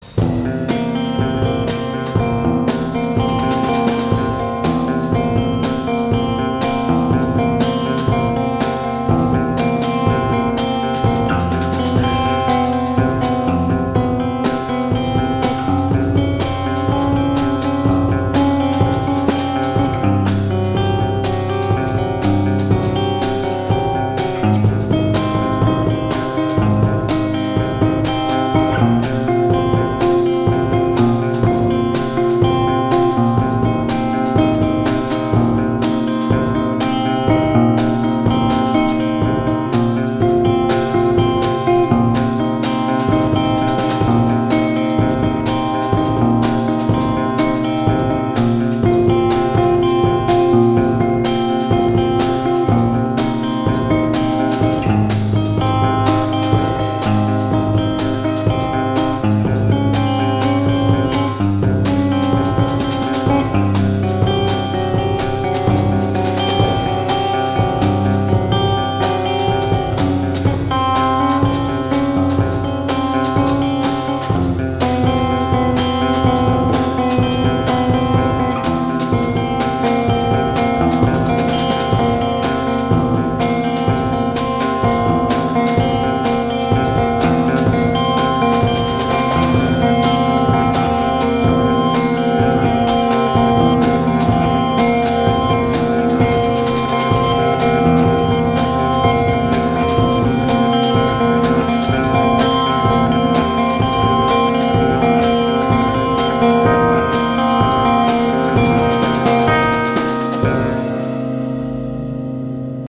enregistré à Herblay